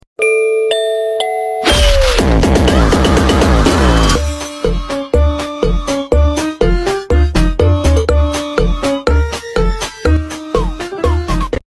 I wanted to add a slashing sound to make it sound more better 😜:: I haven’t seen anyone do something like this so I guess it’s new?